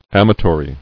[am·a·to·ry]